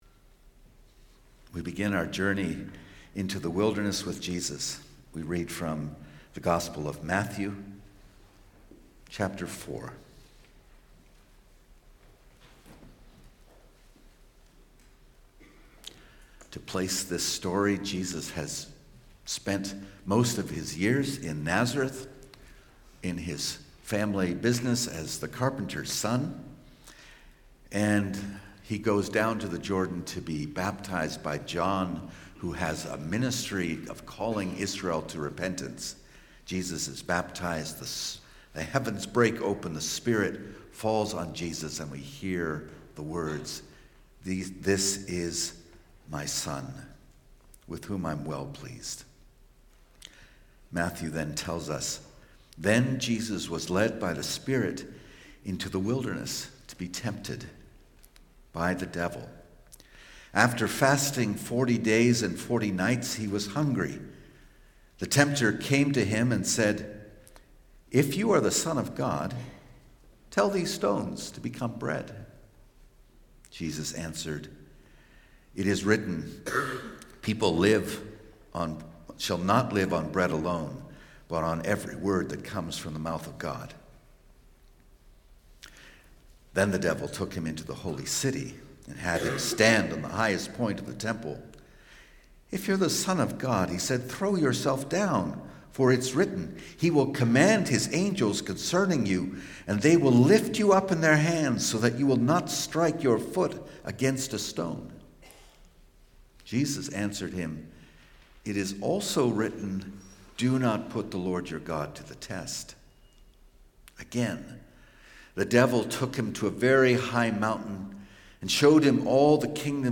Sermons | Community Christian Reformed Church